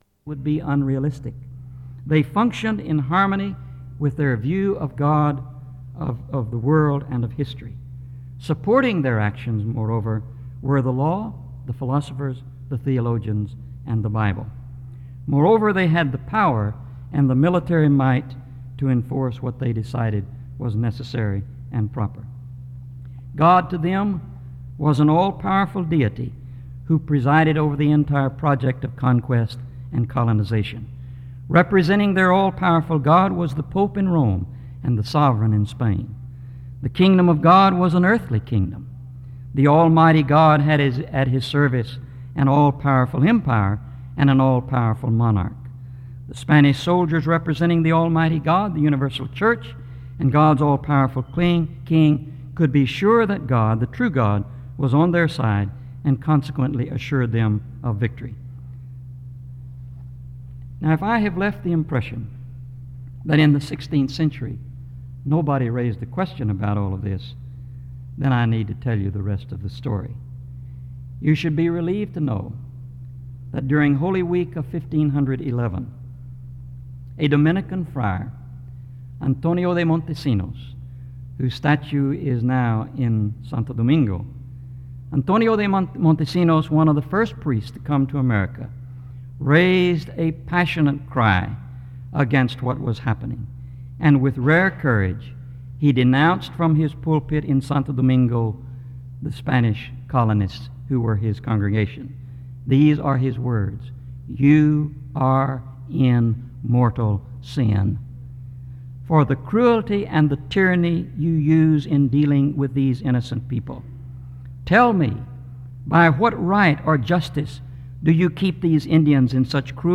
SEBTS Carver-Barnes Lecture
SEBTS Chapel and Special Event Recordings